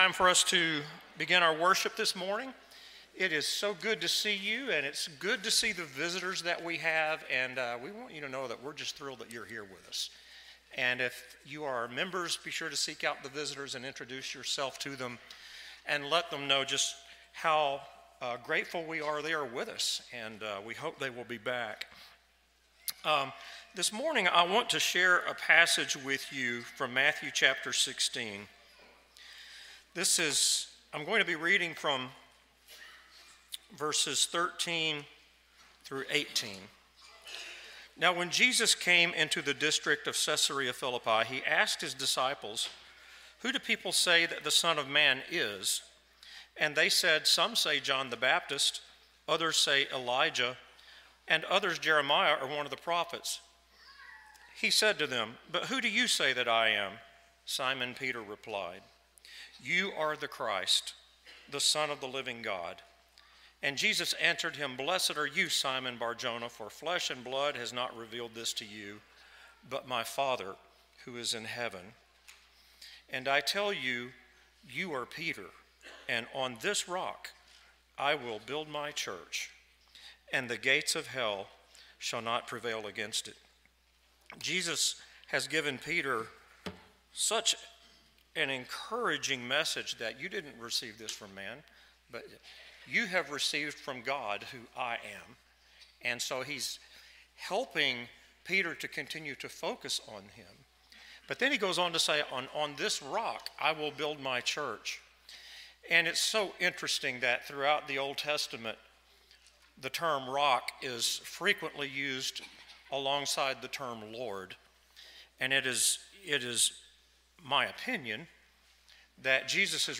James 4:10, English Standard Version Series: Sunday AM Service